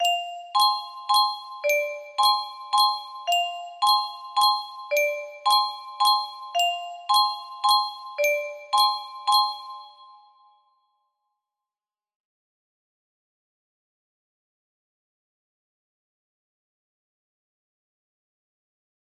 Circus music box melody
Circus